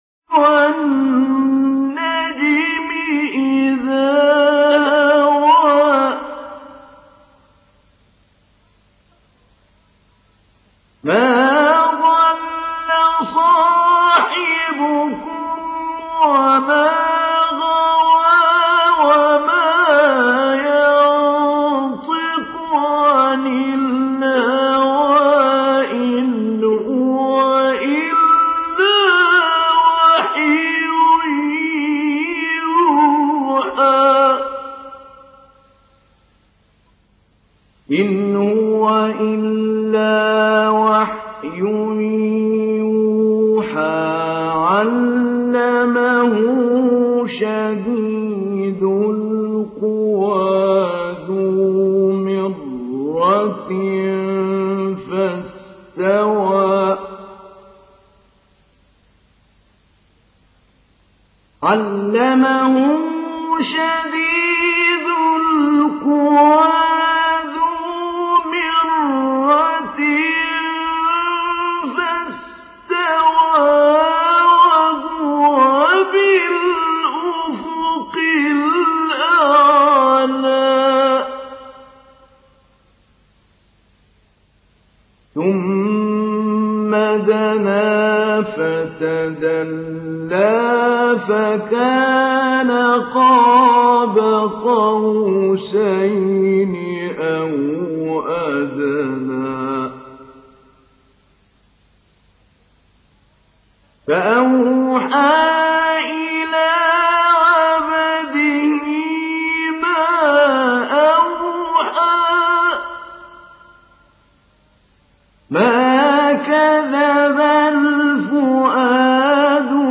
Télécharger Sourate An Najm Mahmoud Ali Albanna Mujawwad